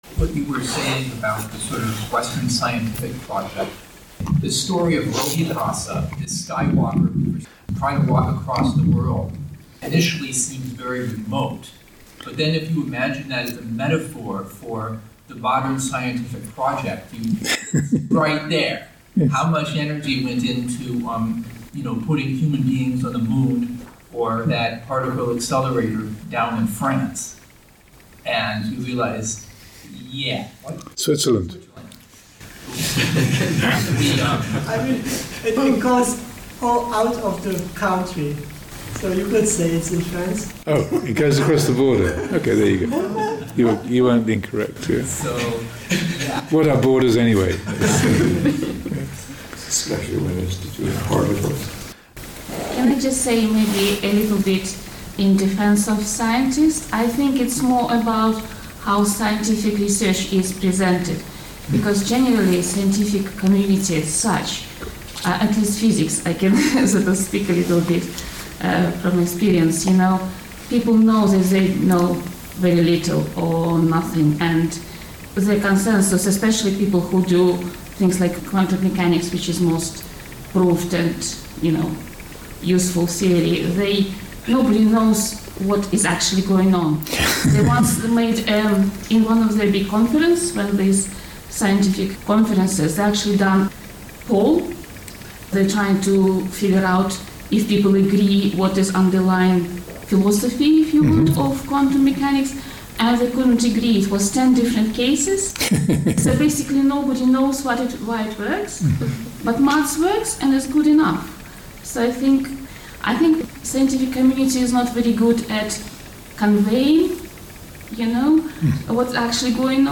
Discussion comparing the modern scientific project and Rohitassa the skywalker’s attempt to reach the end of the world (SN 2.26).